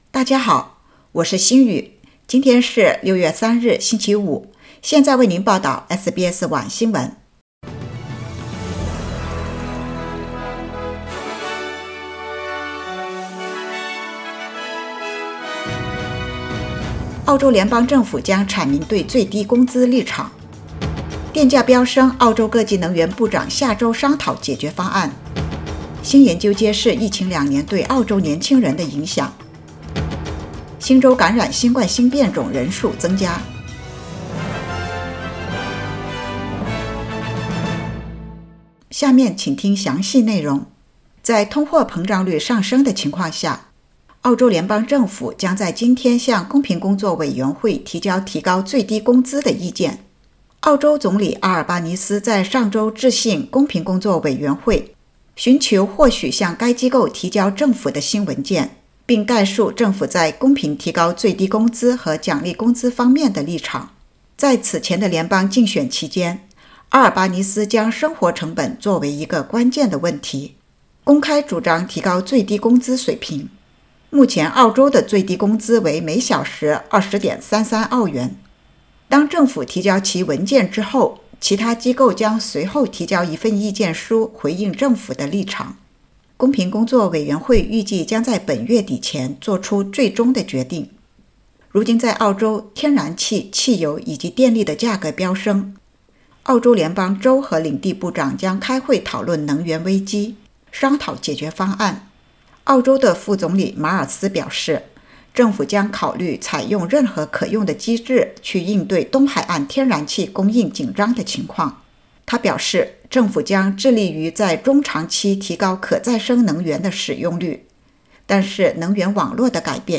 SBS晚新闻（2022年6月3日）